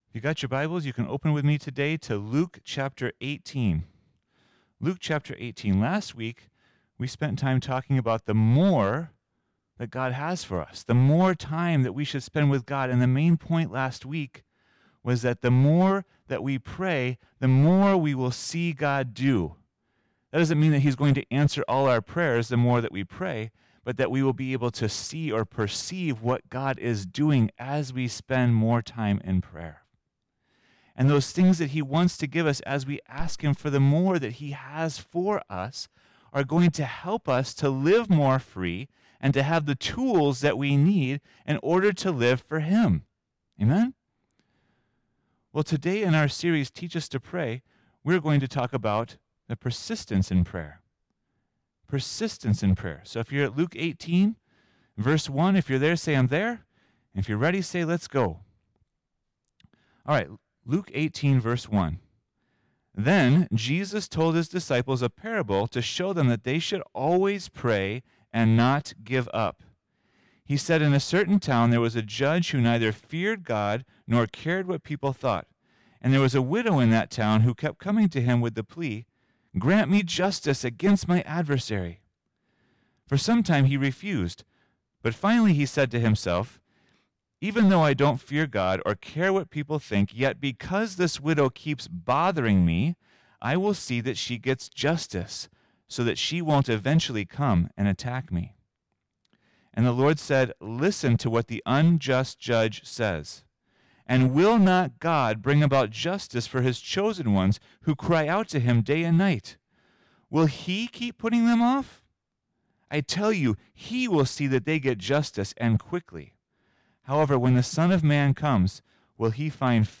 This song was played at the end of the message.